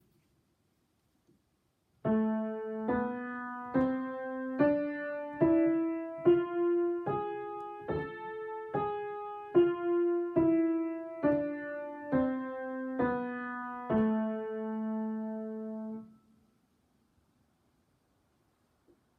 スケール中に３音変化が！和声的短音階
ハ長調の３音下がったイ短調では、第７音である「ソ」をさらに半音上げ「ソ＃」で辿ります。
後半の「ソ＃」が効いて絶妙な音感を誘いますよね。
Aminor_Harmonic.m4a